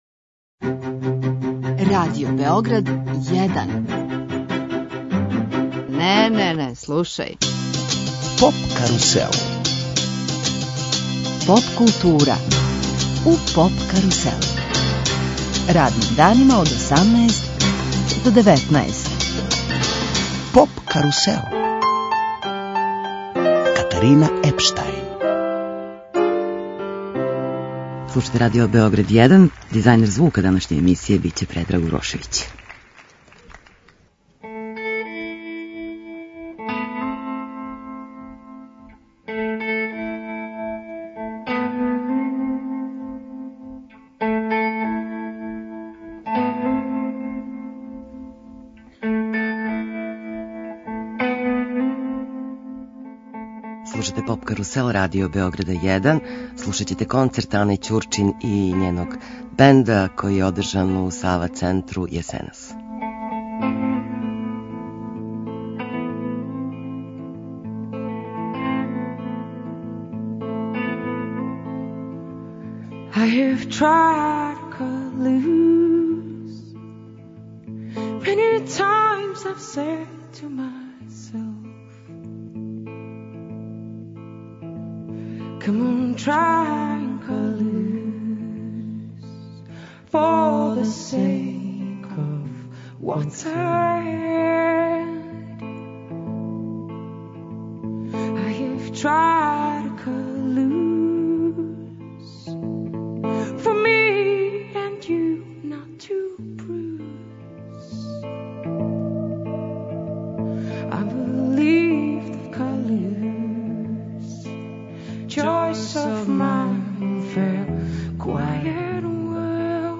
Емитујемо концерте
који су одржани крајем прошле године у Сава центру.